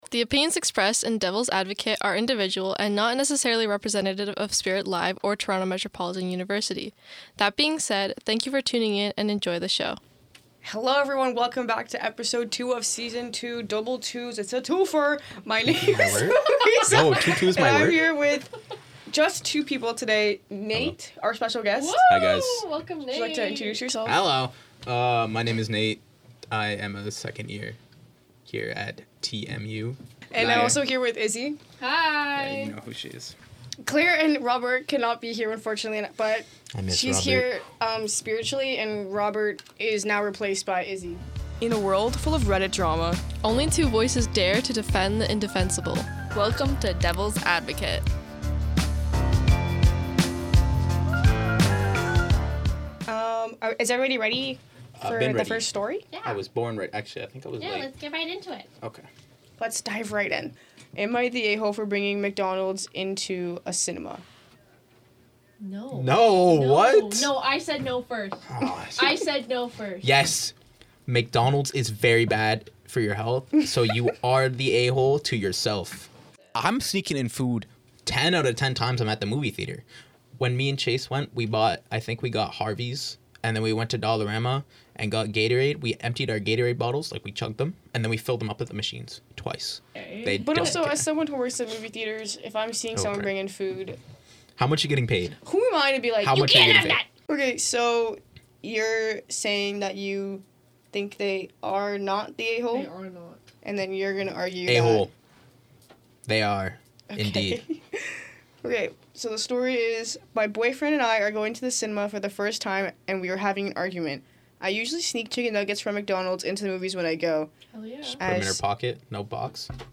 This week, we’re joined by a special guest, down two members, and recorded on a completely wrong microphone.